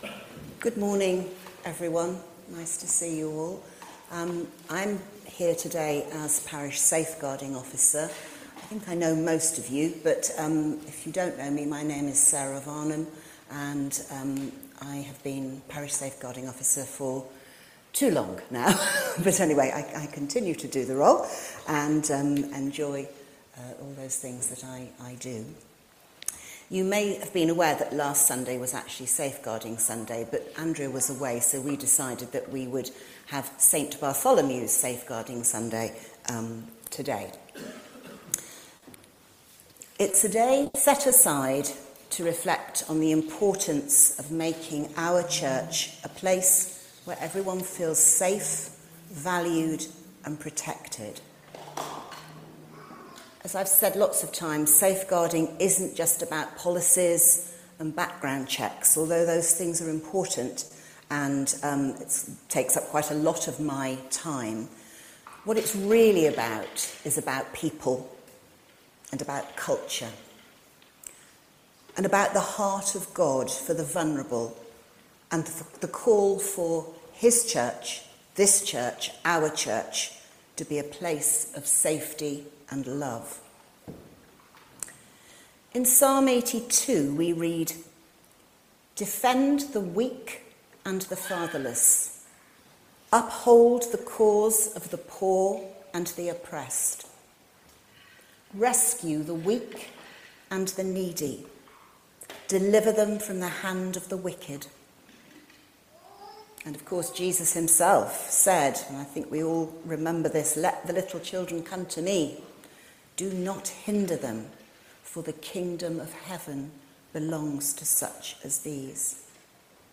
This is the Gospel of the Lord All Praise to you, O Christ Series: Christ the King , Ordinary Time , Safeguarding Sunday , Sunday Morning